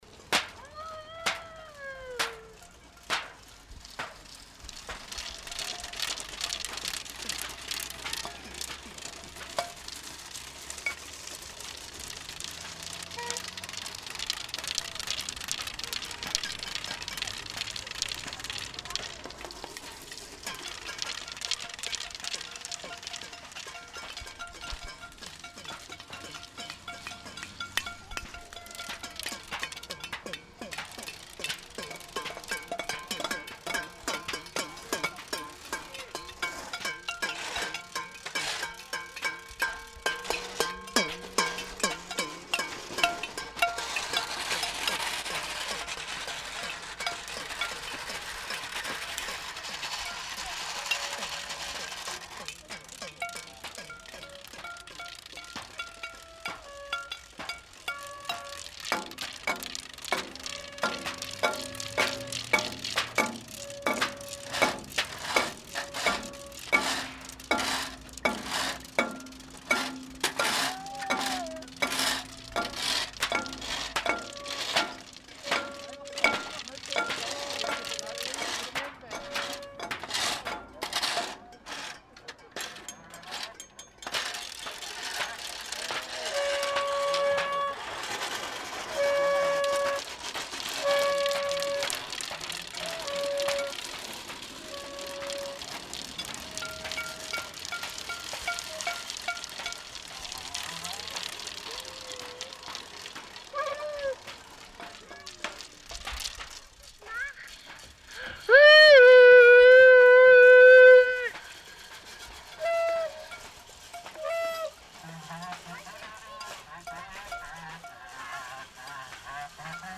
Versammlungsorte: Kunst Halle Sankt Gallen; Palace; Stadtpark (Ecke Museumsstrasse/Parkstrasse) Der Künstler und Theatermacher Tim Etchells lädt alle Bewohnerinnen und Besucher St. Gallens dazu ein, gemeinsam eine Stunde lang Lärm in den Strassen der Stadt zu erzeugen. Alle sind herzlich willkommen, sich an drei bestimmten Orten zu versammeln, ausgerüstet mit Instrumenten, Pfeifen, Sirenen, Rasseln, Töpfen, Pfannen und anderen Objekten, mit denen Lärm produziert werden kann.